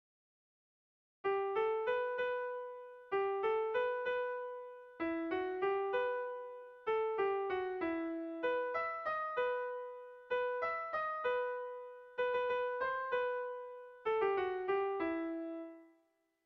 Sehaskakoa
Lau puntuko berdina, 8 silabaz
ABDE